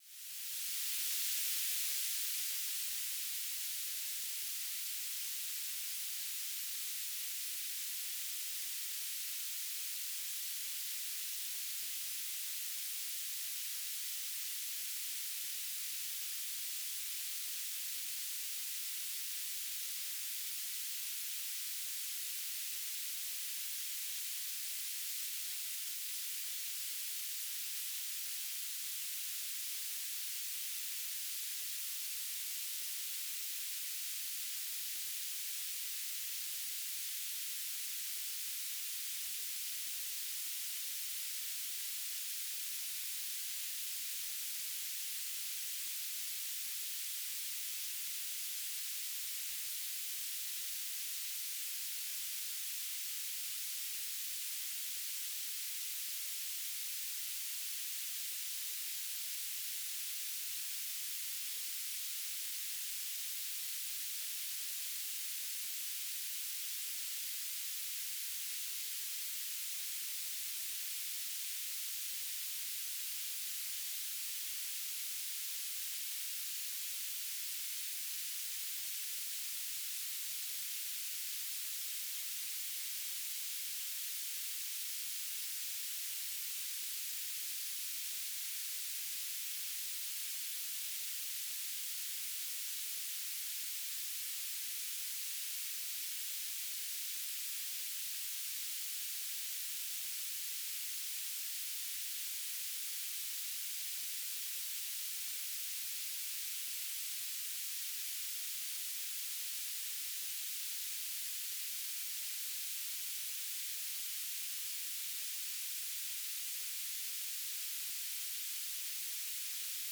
"transmitter_description": "BPSK1k2 TLM",
"transmitter_mode": "BPSK",